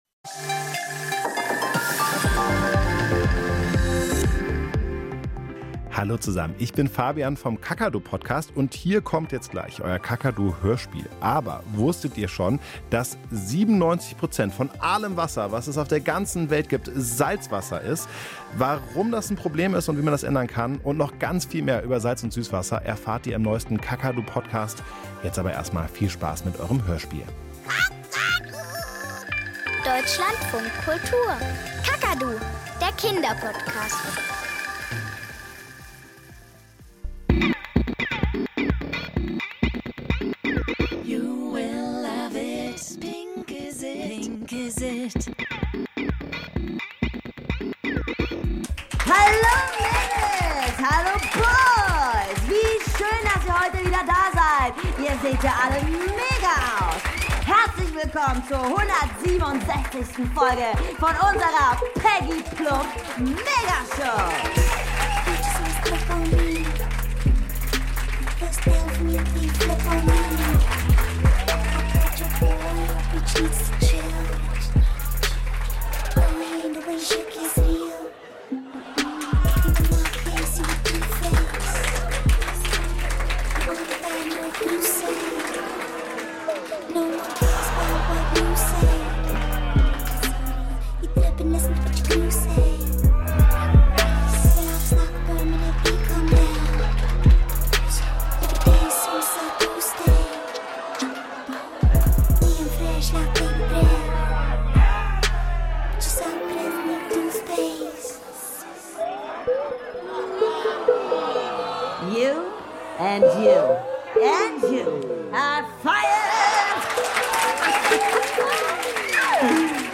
Kinderhörspiel - Die Trockenhaubenverschwörung ~ Kakadu – Das Kinderhörspiel Podcast